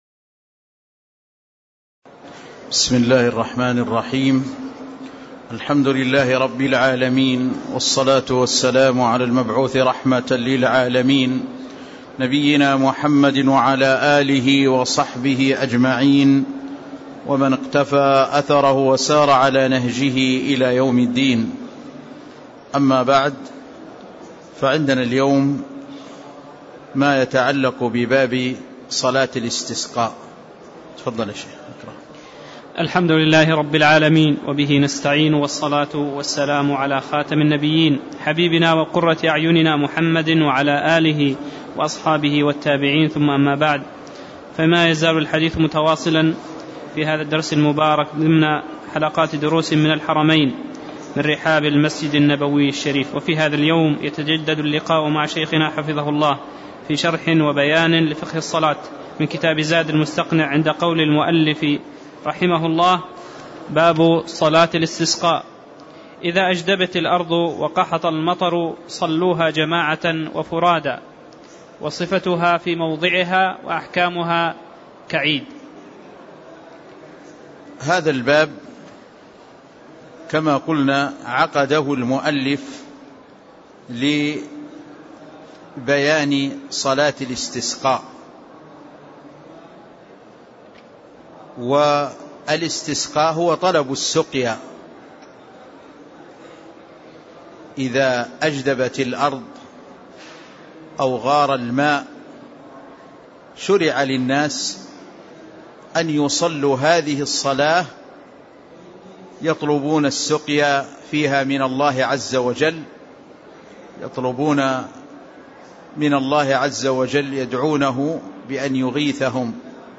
تاريخ النشر ١٥ ربيع الأول ١٤٣٦ هـ المكان: المسجد النبوي الشيخ